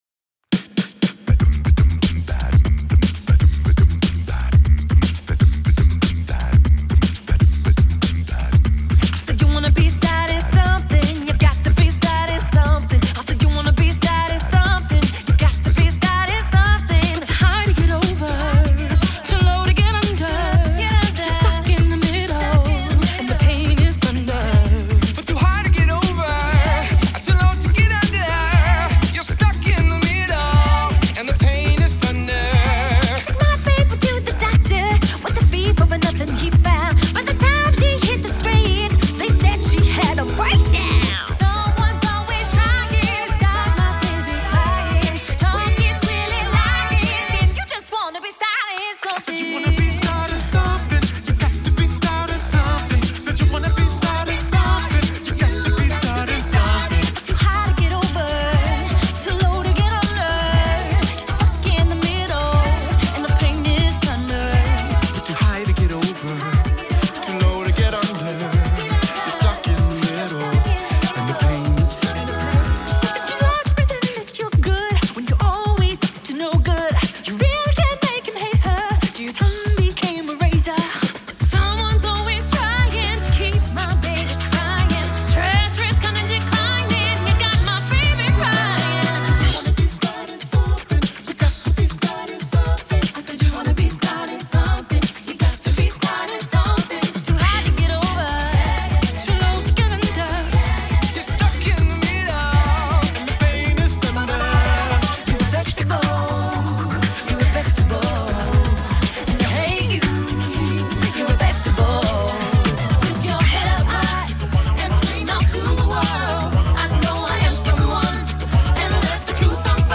Genre: Pop, Classical